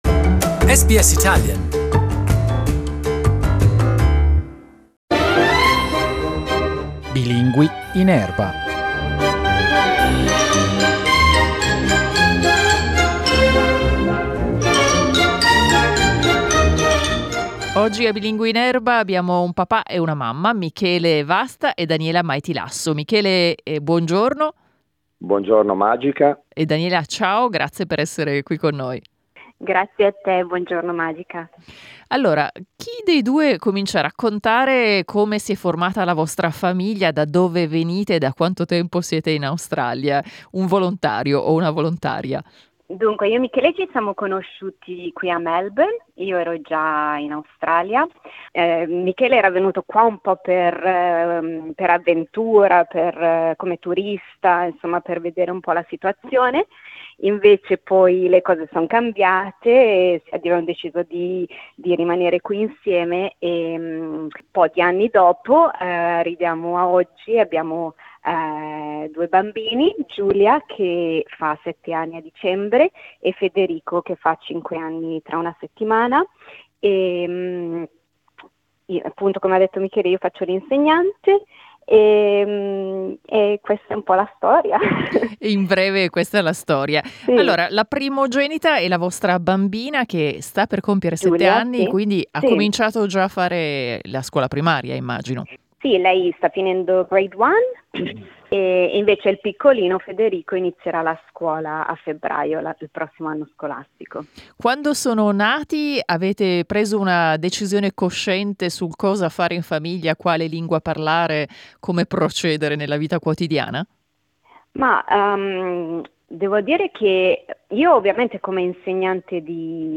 Two Italian parents of two Australian-born kids talk about the challenges of keeping the Italian language alive for their kids while living in Melbourne.